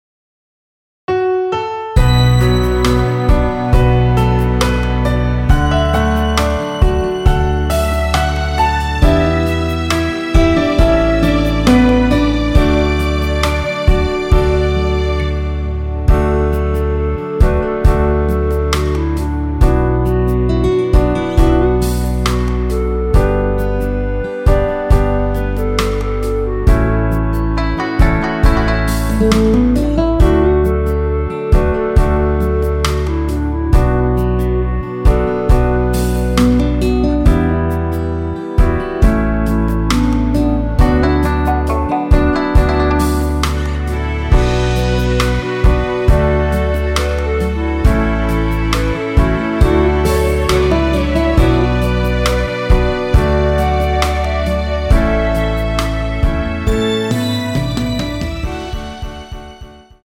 원키에서(-1)내린 멜로디 포함된 MR입니다.
D
앞부분30초, 뒷부분30초씩 편집해서 올려 드리고 있습니다.
중간에 음이 끈어지고 다시 나오는 이유는